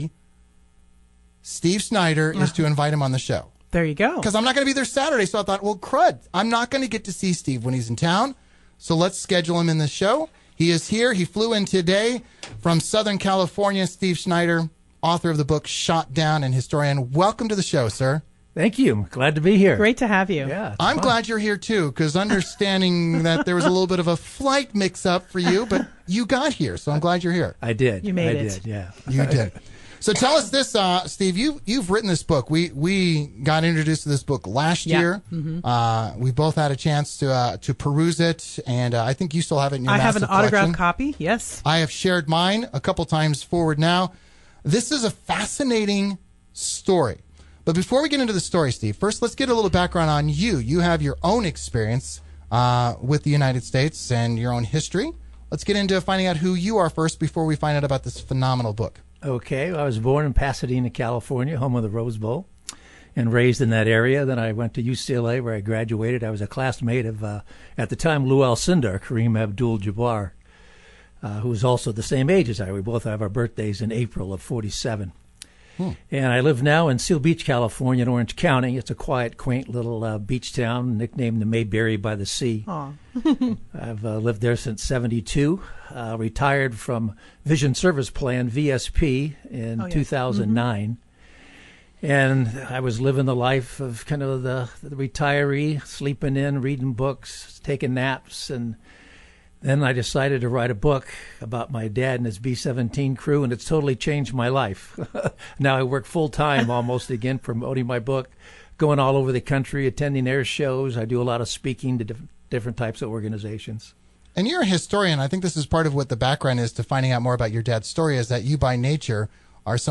Interviewed
on 1430 AM KYKN Radio in Salem, Oregon